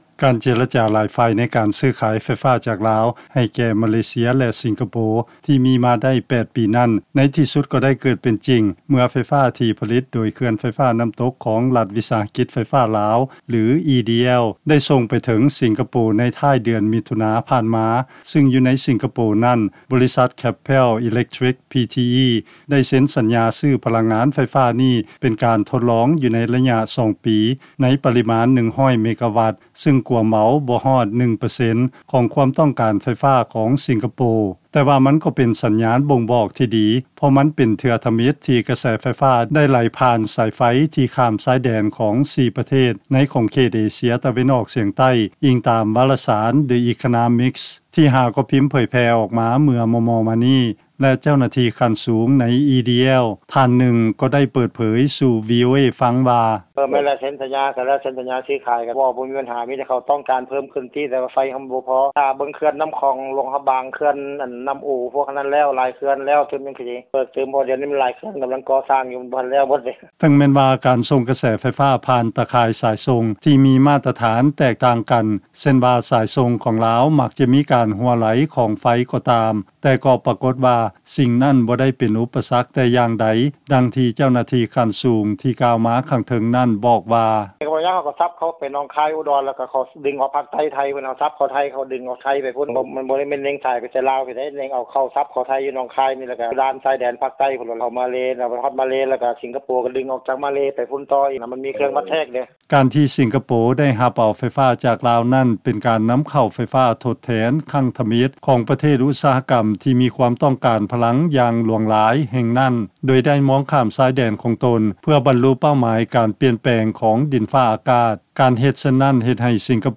ເຊີນຟັງລາຍງານ ການຂາຍໄຟຟ້າໃຫ້ ມາເລເຊຍ ແລະ ສິງກະໂປ ທີ່ເປັນການທົດລອງ ທັງຈະມີຜົນບວກ ແລະ ລົບ ແກ່ປະເທດລາວ